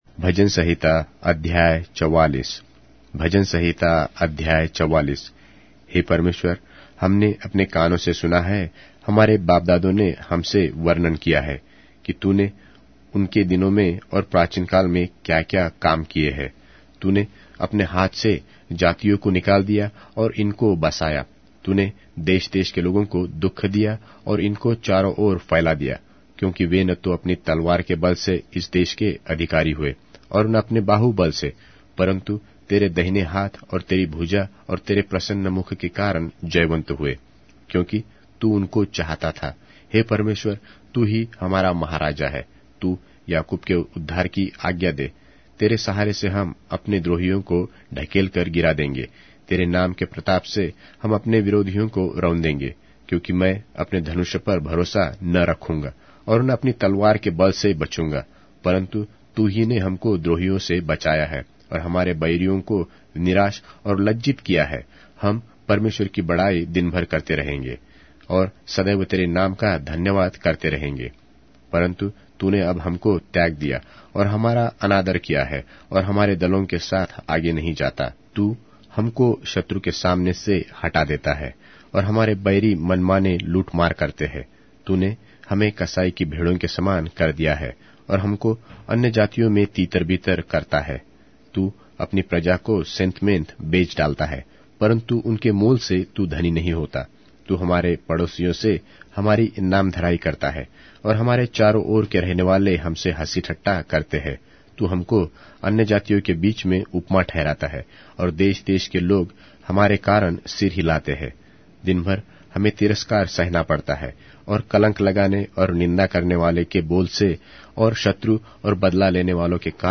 Hindi Audio Bible - Psalms 8 in Ocvbn bible version